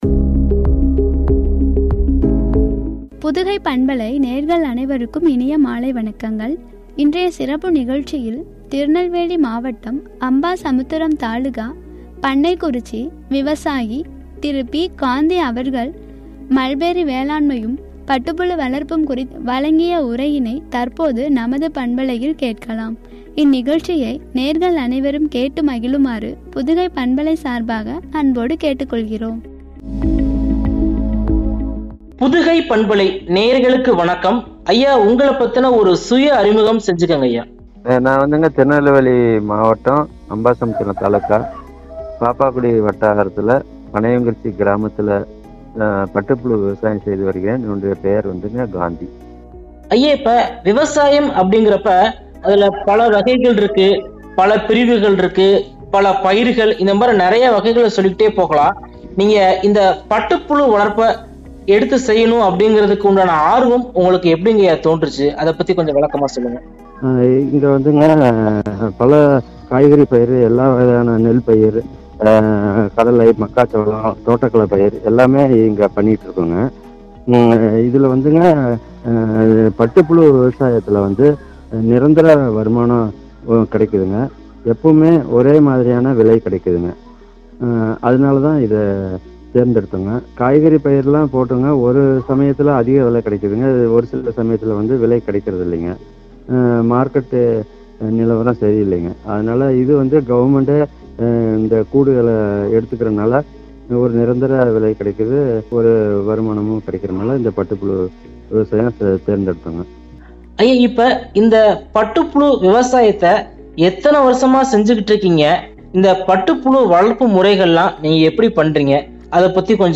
பட்டுப்புழு வளர்ப்பும்” குறித்து வழங்கிய உரையாடல்.